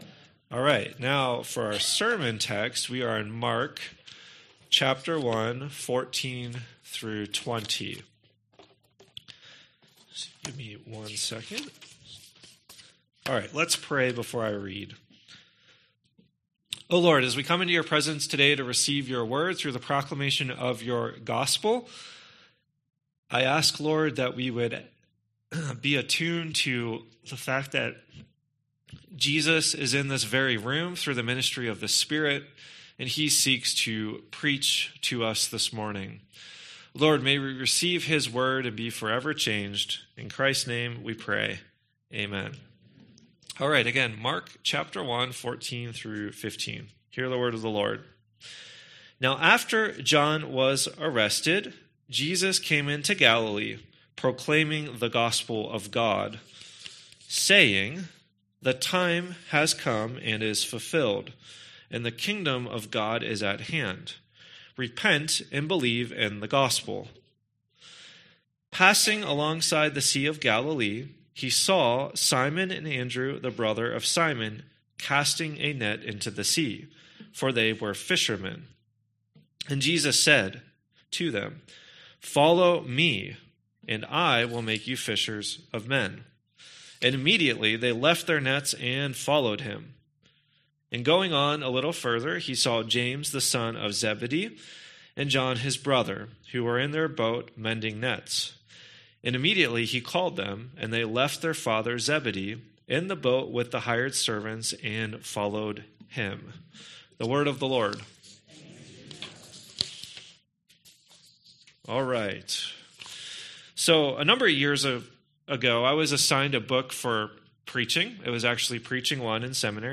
Sermon text: Mark 1:14-20